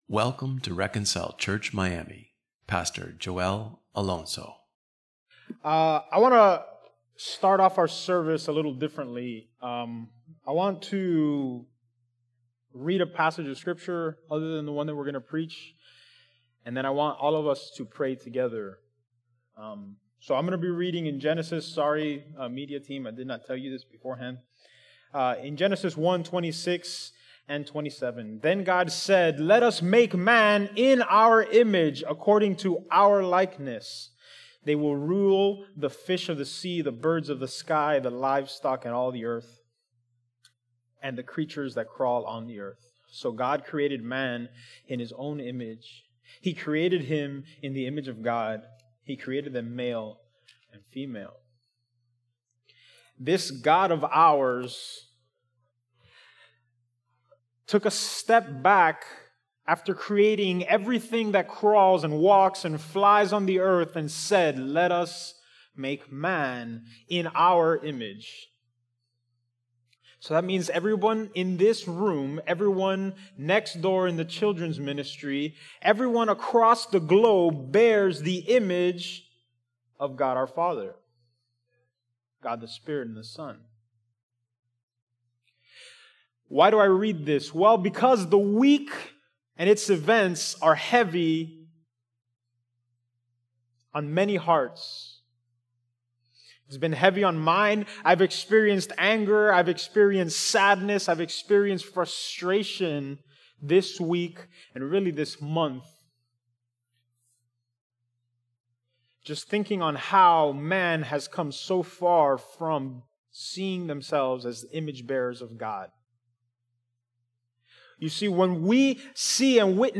Psalms 36:1-12 Independent Sermon Series Delivered on